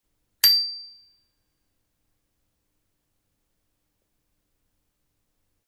Klokken har en klar, høj klang, som nok skal blive hørt af andre cyklister og fodgængere.
• Type: Fjederhammer
Produkt Ringeklokke